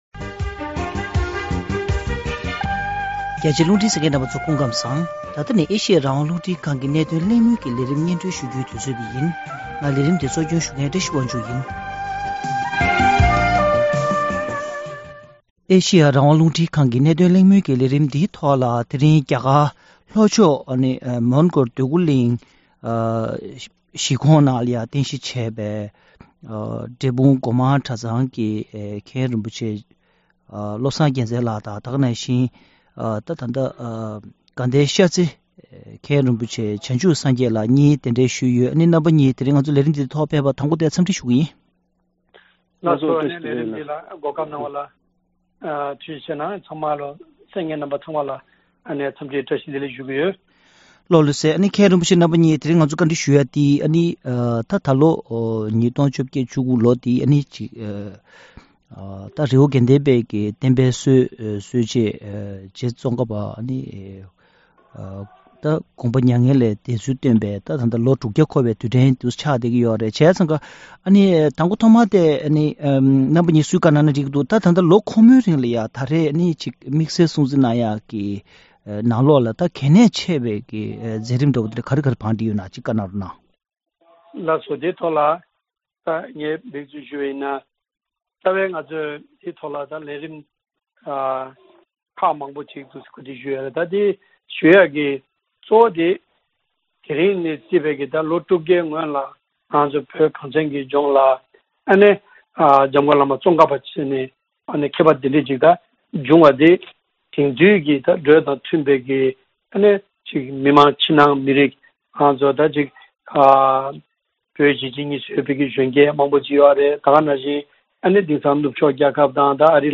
༄༅། །གནད་དོན་གླེང་མོལ་གྱི་ལས་རིམ་ནང་།